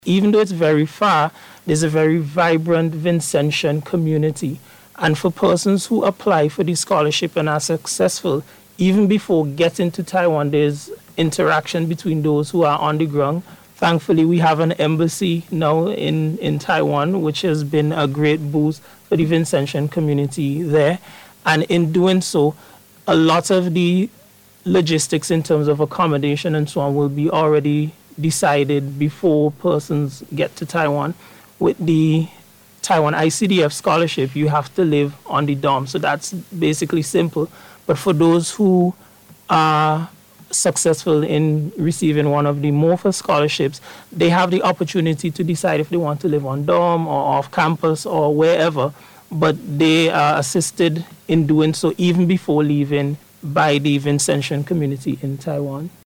was speaking on NBC’s Face-to Face Programme this morning